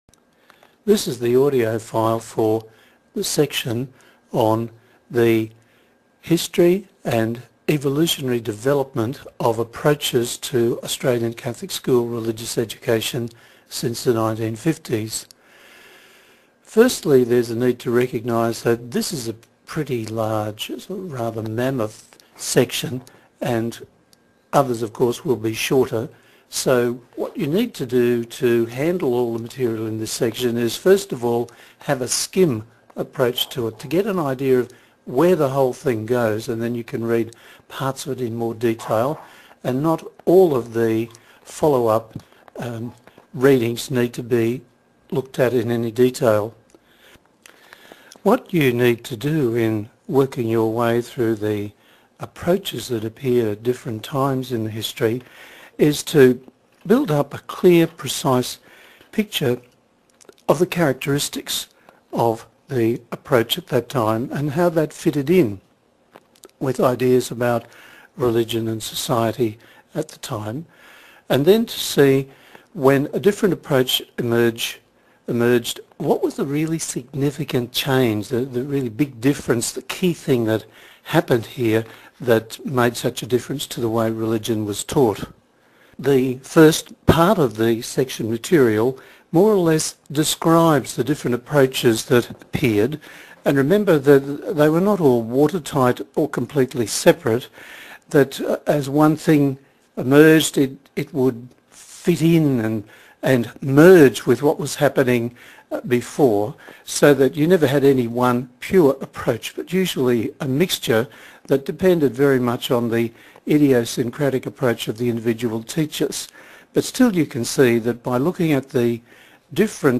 2. The audio file lecture Click the icon to hear or download the mp3 audio lecture file. (49 minutes) The audio lecture fills out the content and is complemented by the Section text below.